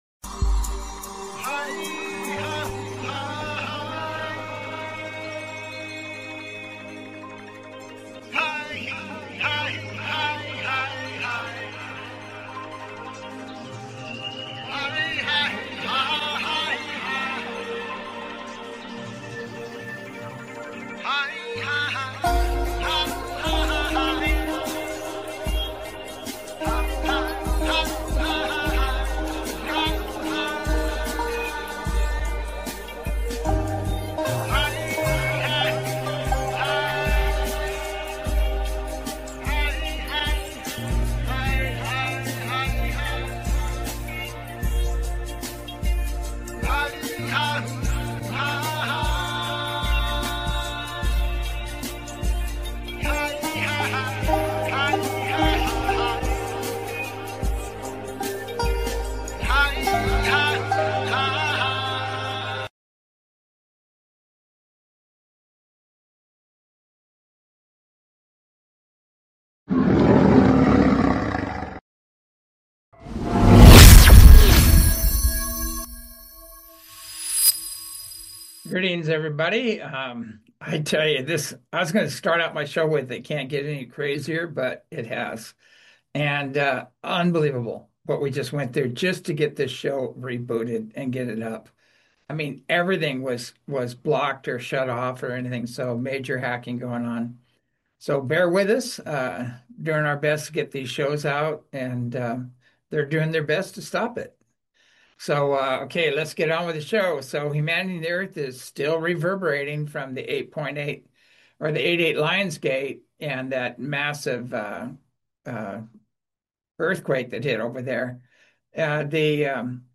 Talk Show Episode, Audio Podcast, As You Wish Talk Radio and Sequence on , show guests , about Sequence, categorized as Earth & Space,News,Paranormal,UFOs,Philosophy,Politics & Government,Science,Spiritual,Theory & Conspiracy